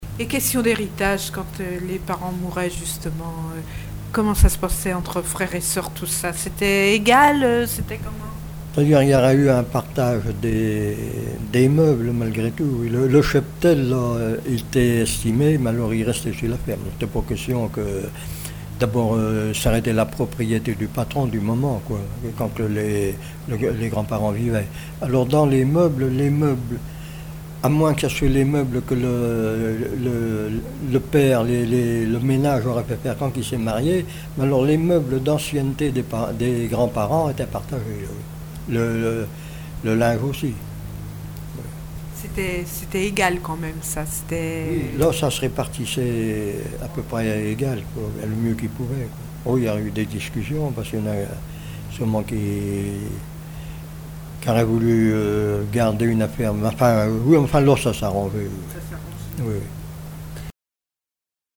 Témoignages de vie
Catégorie Témoignage